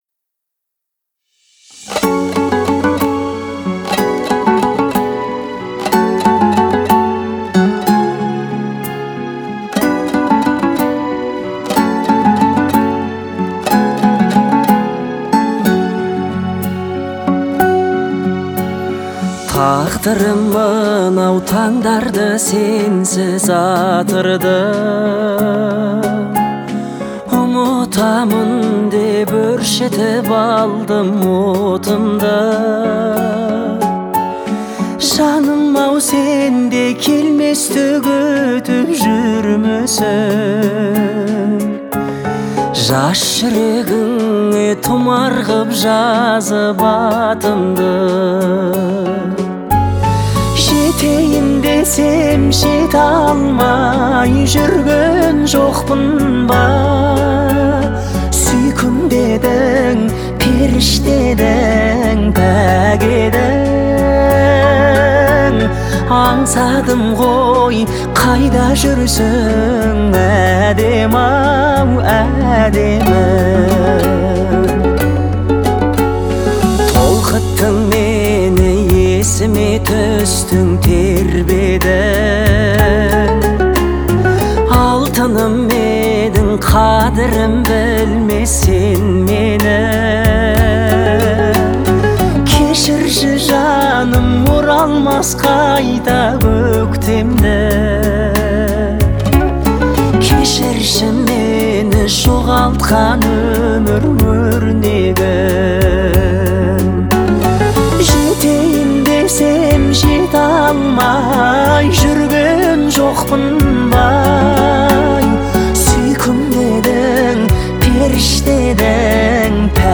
жанру казахской поп-музыки